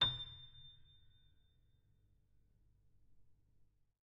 sampler example using salamander grand piano
A7.ogg